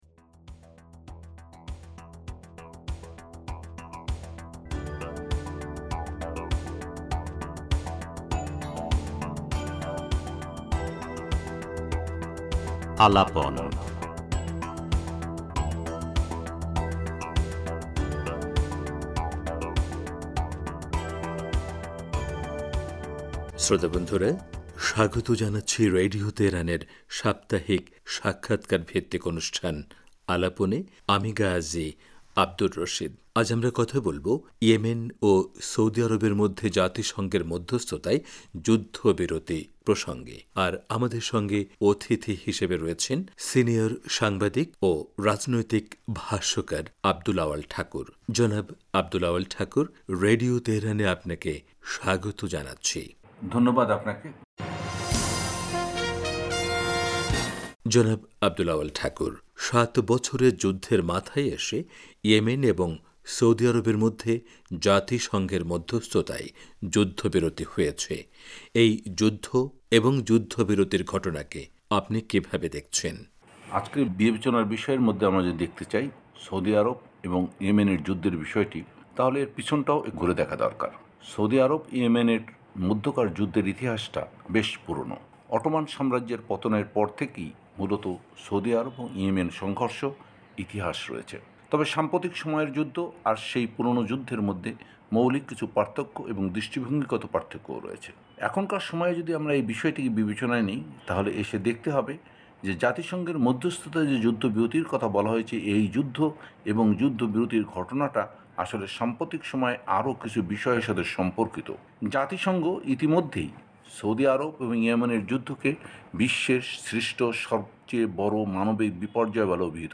পুরো সাক্ষাৎকারটি তুলে ধরা হলো।